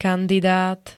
kandidát [-d-] -ta pl. N -ti m.
Zvukové nahrávky niektorých slov